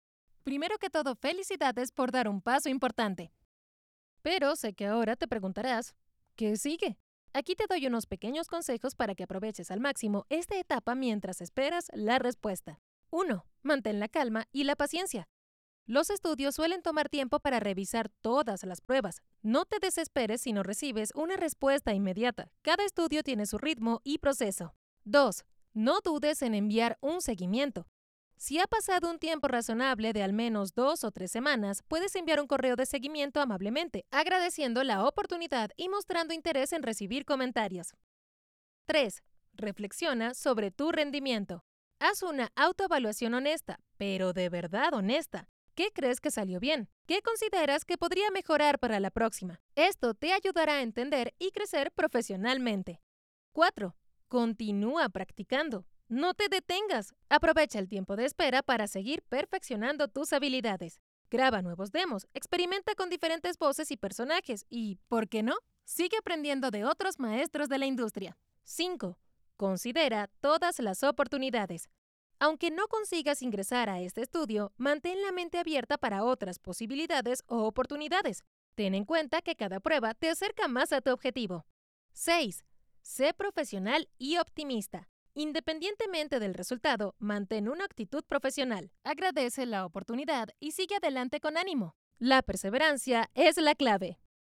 Muestra Voz Contenido para Redes
Latin neutral Spanish